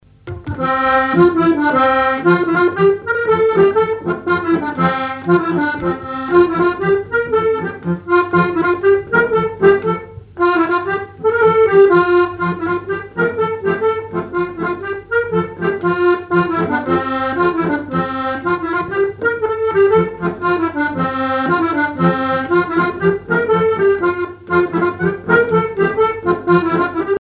Mémoires et Patrimoines vivants - RaddO est une base de données d'archives iconographiques et sonores.
Chants brefs - A danser
danse : branle : grenoïe
accordéon chromatique
Pièce musicale inédite